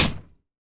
PUNCH G   -S.WAV